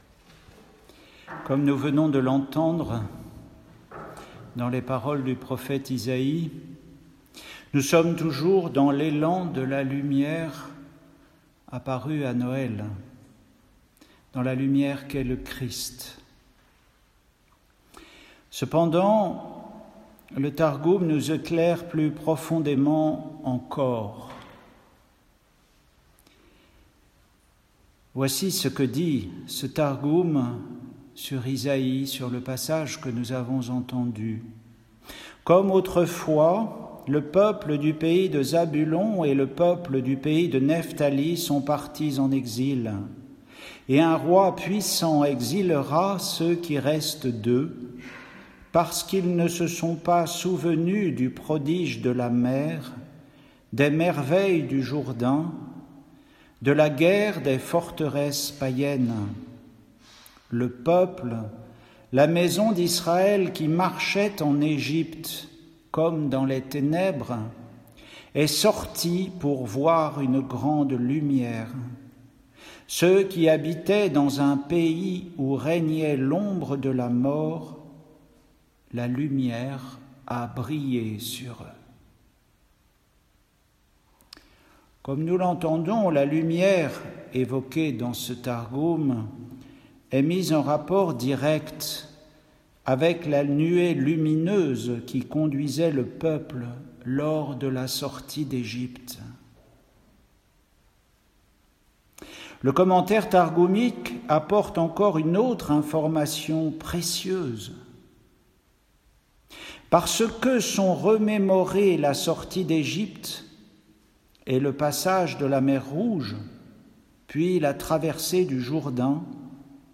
Retrouvez les méditations d’un moine sur les lectures de la messe du jour.
Homélie pour le 3e dimanche du Temps ordinaire